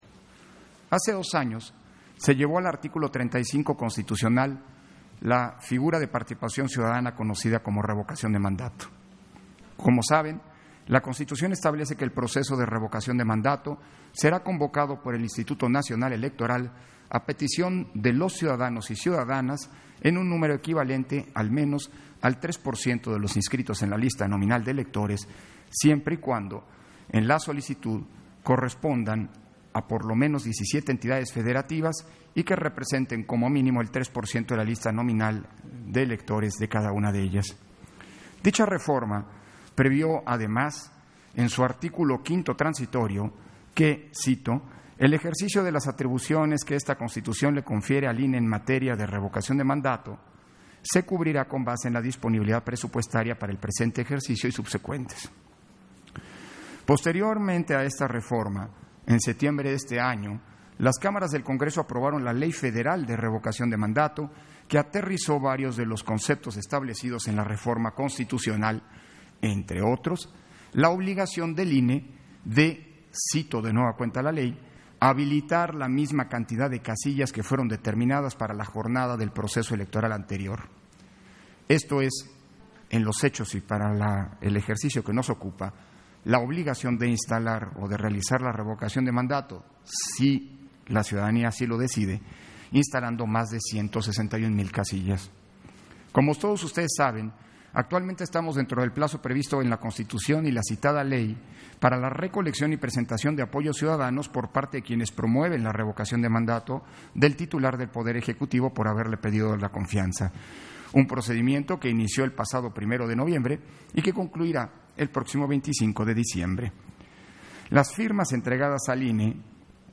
171221_AUDIO_INTERVENCIÓN-CONSEJERO-PDTE.-CÓRDOVA-PUNTO-ÚNICO-SEGUNDA-SESIÓN-EXT. - Central Electoral